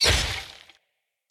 weapon audio
swordImpact2.ogg